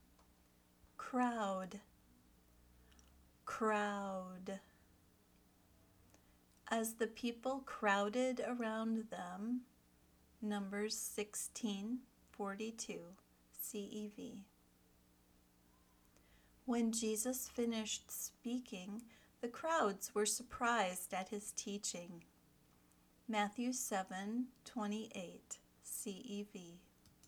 kraʊd   (in this story, “crowd” is a verb)
vocabulary word – crowd